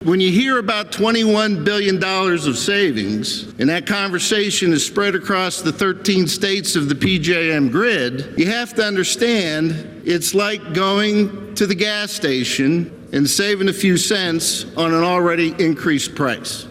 In comments on the floor of the PA Senate, Pittman was critical of Shapiro and the deal with PJM.  While he said he was happy with the Governor’s focus on the problem with electricity prices, there is still going to be increases seen.